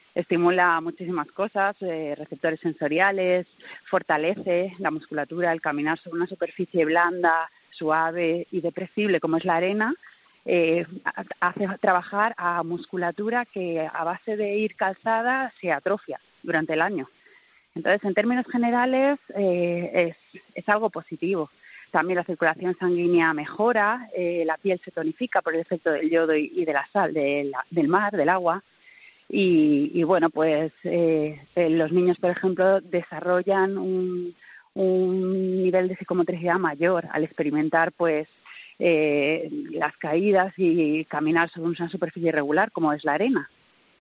Una podóloga habla de los beneficios de andar descalzo sobre la arena